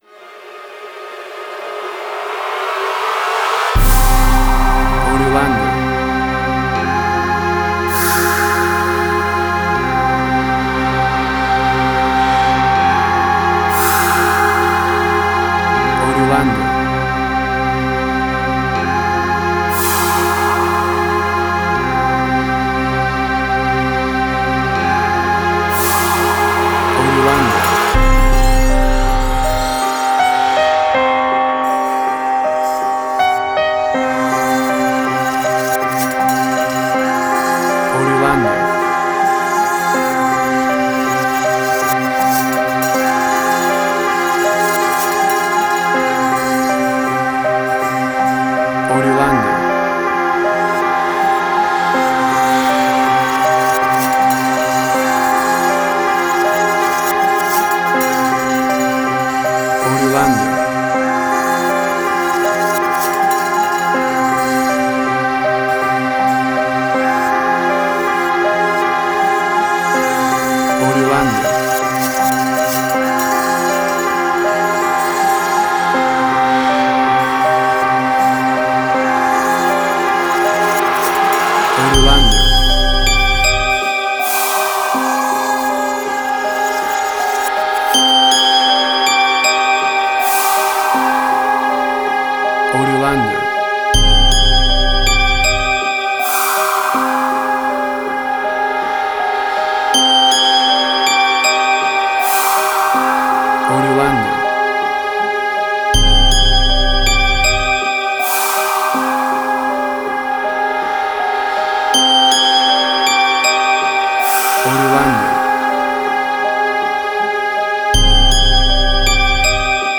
WAV Sample Rate: 16-Bit stereo, 44.1 kHz
Tempo (BPM): 80